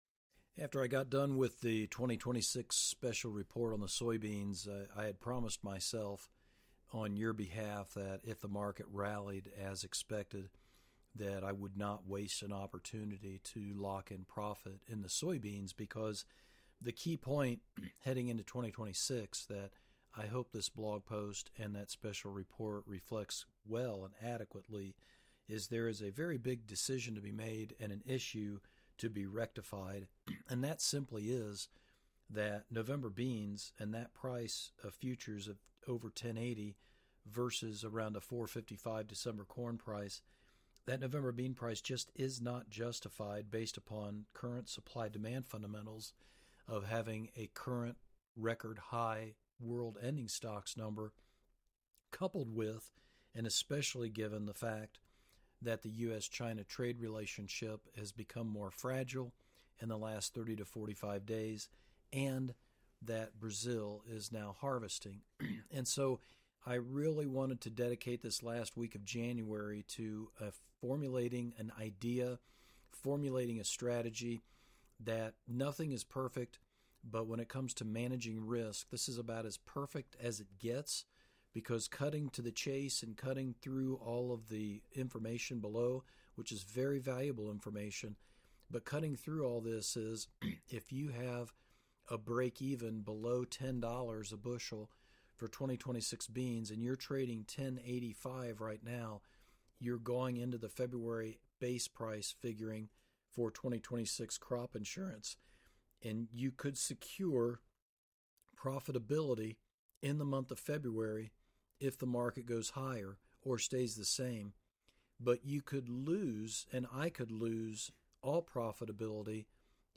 #1 Audio Commentary Laying-Out This Strategy & Major Points